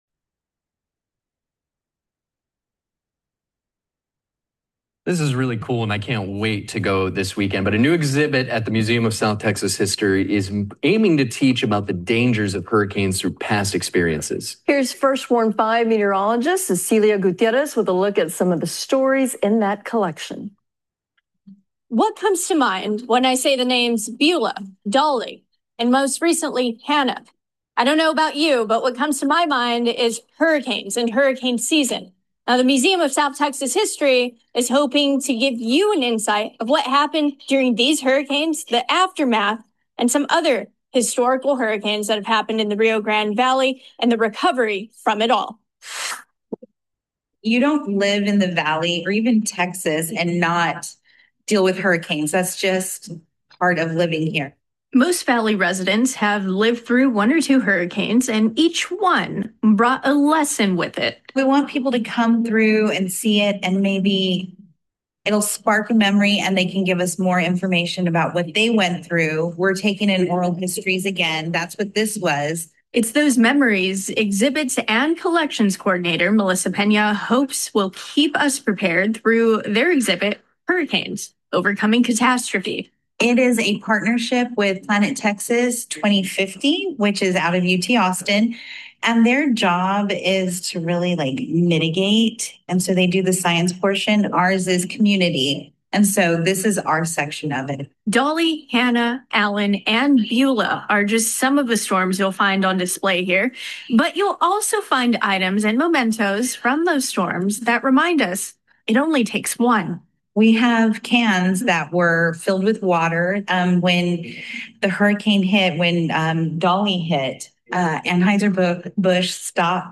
Audio News Report Museum Hurricane Exhibit